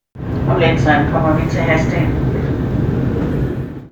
Højttalerudkald - "Næste station.."
hadsten_manuelt.mp3